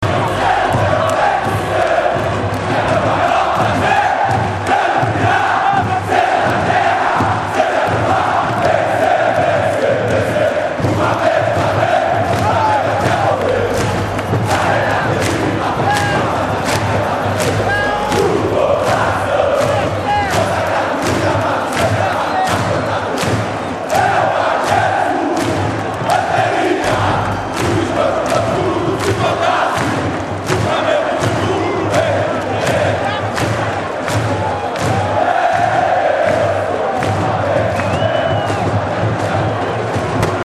un coro dei tifosi del Flamengo, spettacolare.